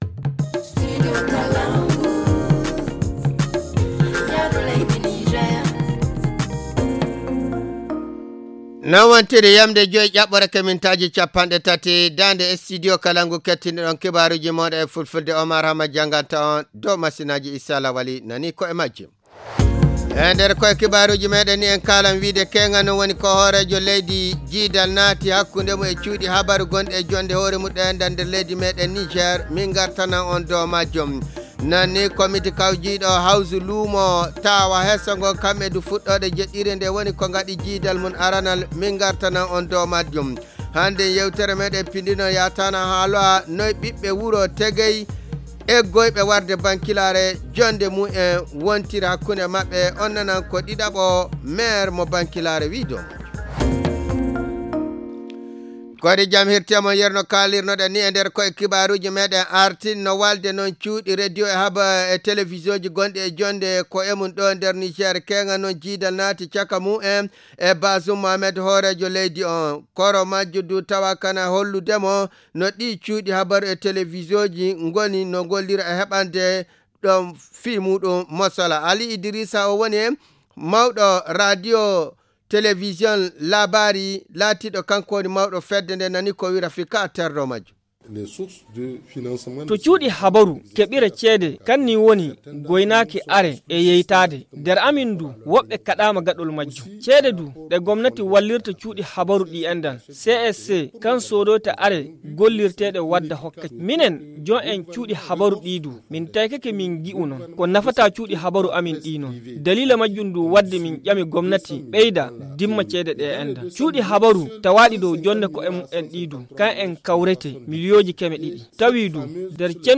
Le journal du 8 février 2023 - Studio Kalangou - Au rythme du Niger